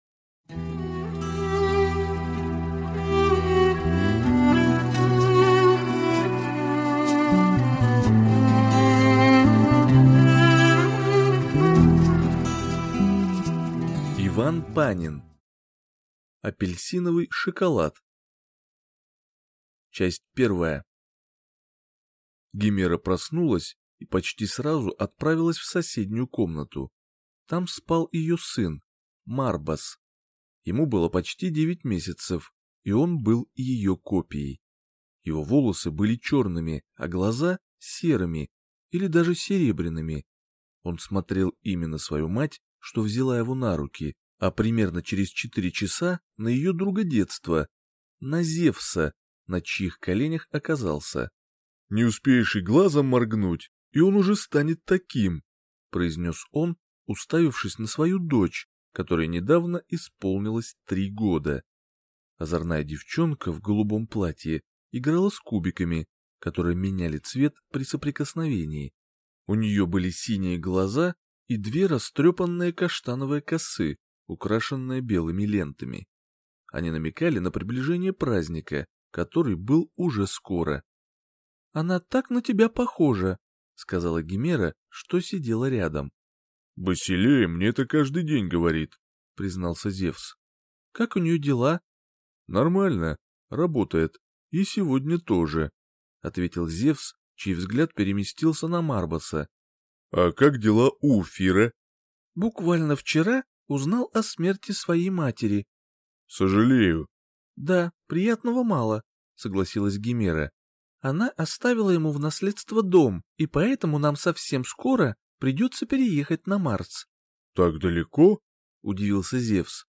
Аудиокнига Апельсиновый шоколад | Библиотека аудиокниг